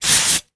perfumesquirts
描述：A body spray bottle is spritzed. The gain has been increased in Pro Tools.
标签： aerosol perfume spray squirt
声道立体声